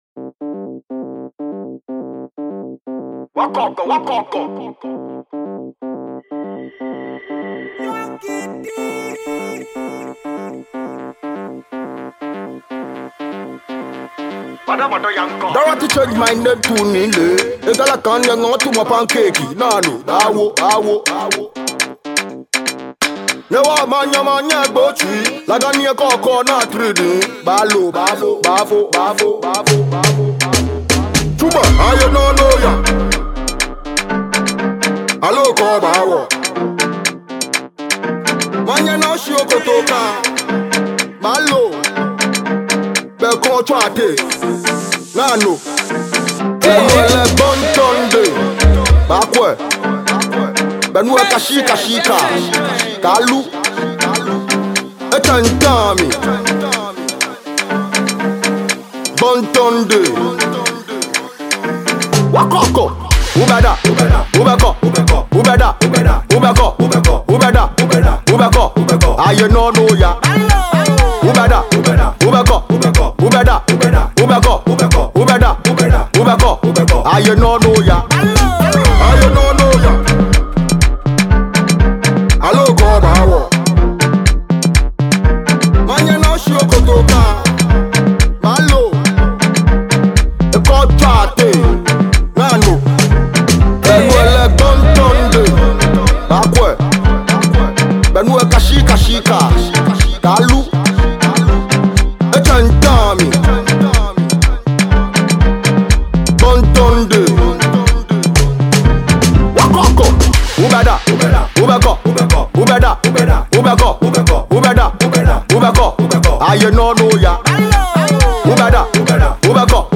and this is a danceable tune for you.